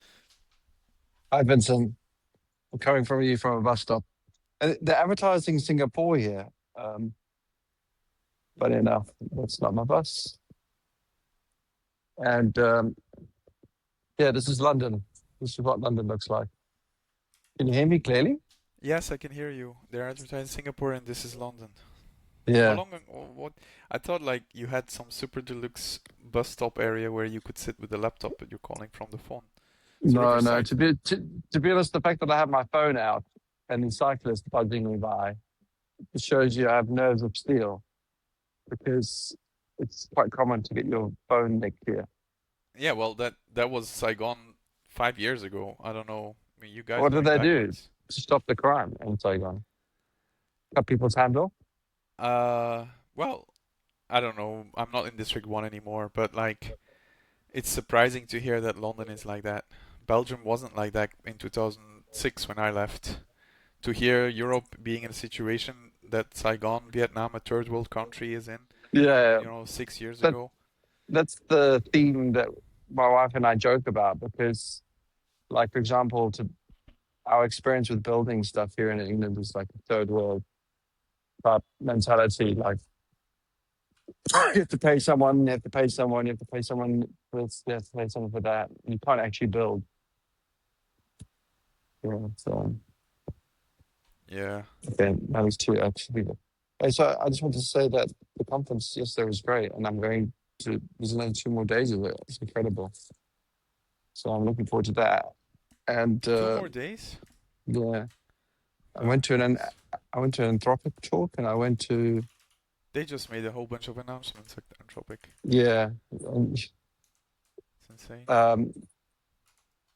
London bus stop, AI Engineer Europe conference